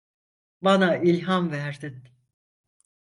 Pronounced as (IPA) /il.hɑm/